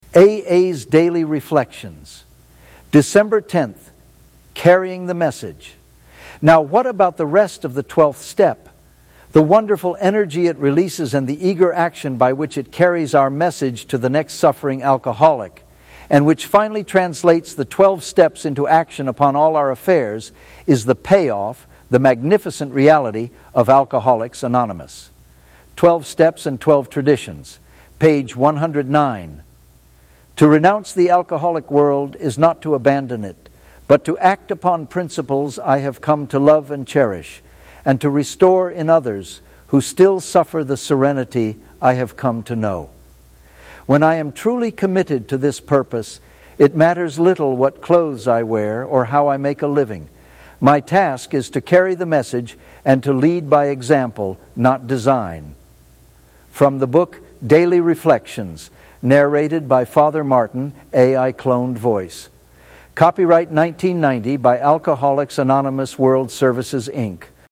Cloned Voice.